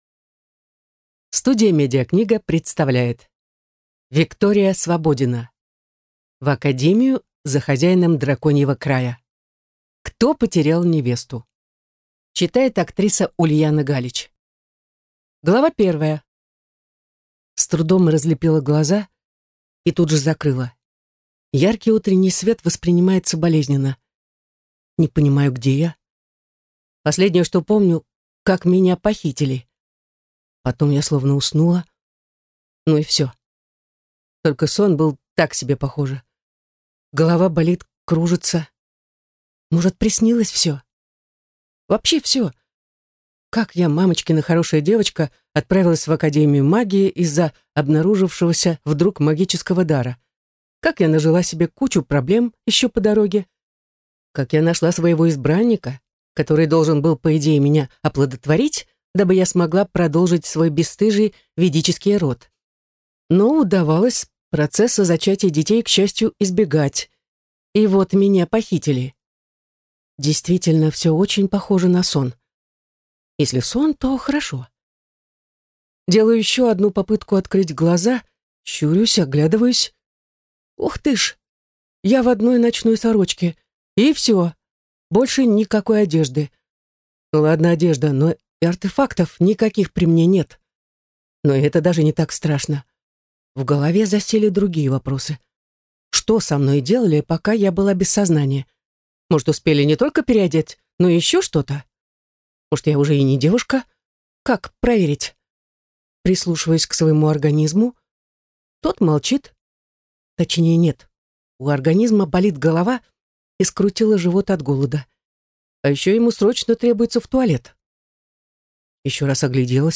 Аудиокнига В академию за хозяином Драконьего Края. Кто потерял невесту?